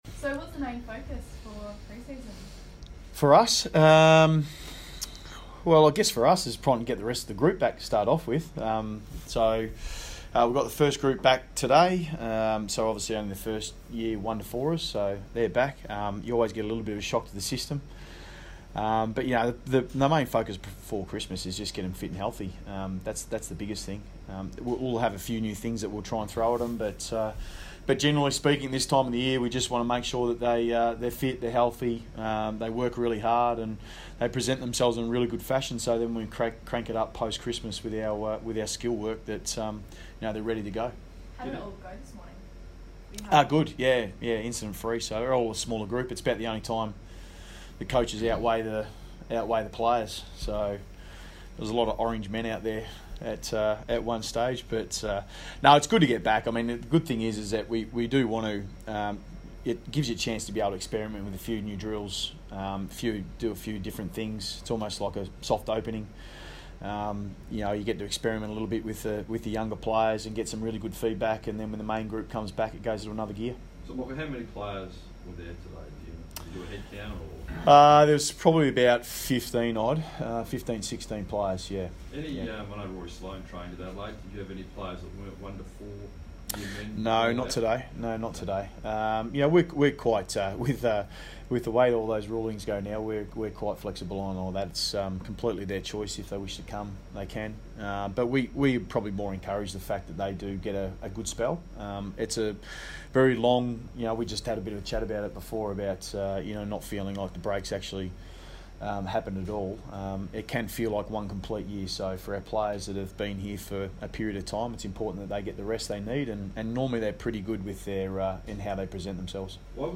Michael Voss press conference - Monday 5 November